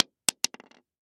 Звуки ореха
один орех упал на пол